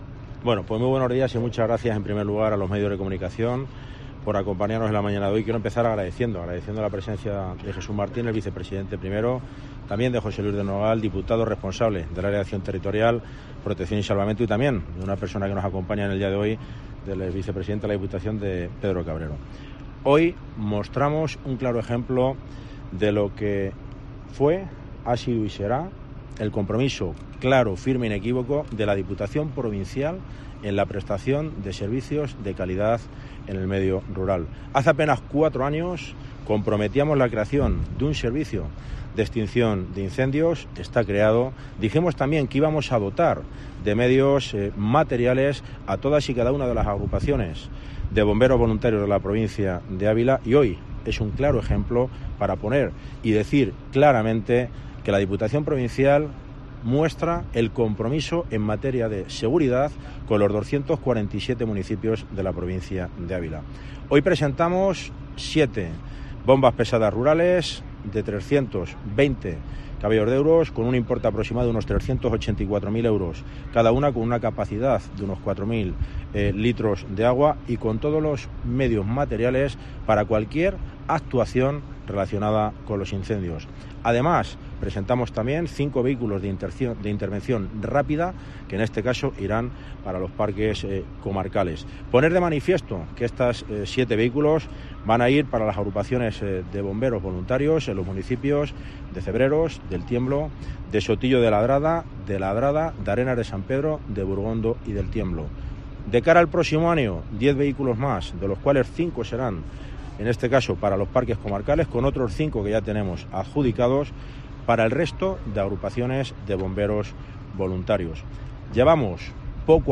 AUDIO / El presidente de la Diputación Carlos García - Bomberos